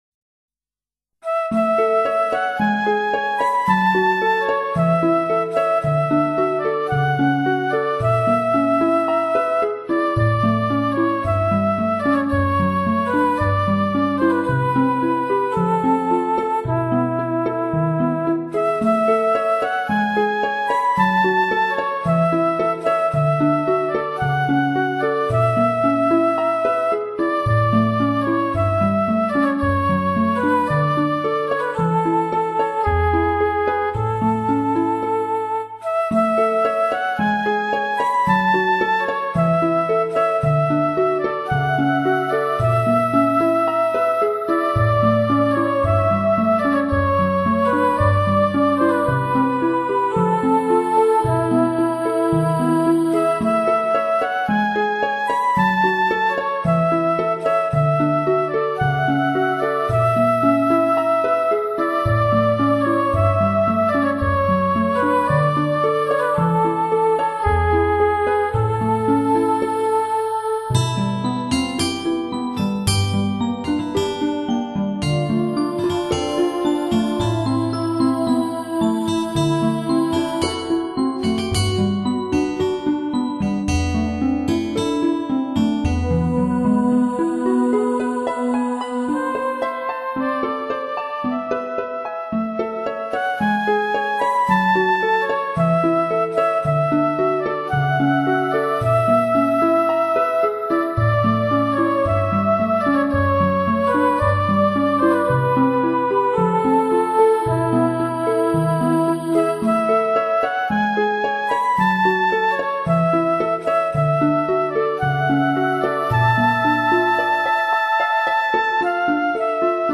音乐类型：纯音乐